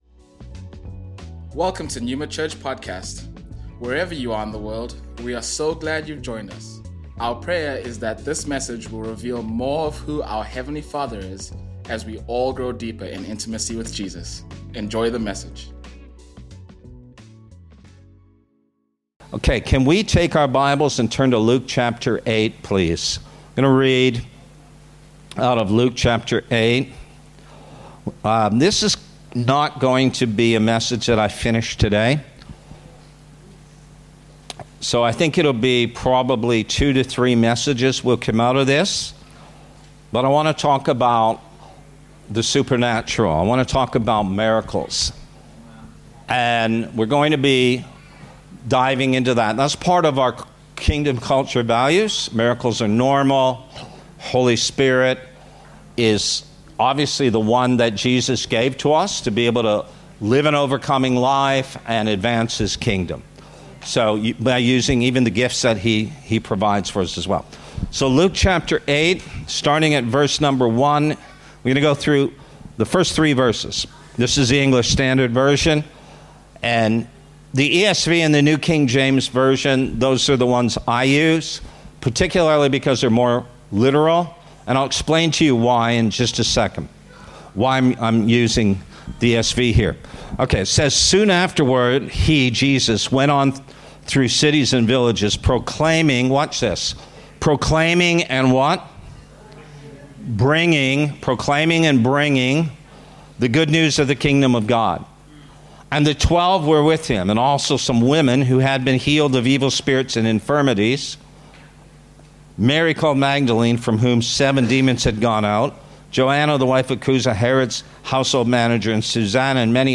Neuma Sunshine Coast Originally recorded on Sunday, 26th January 2025 | 9:30am &nbsp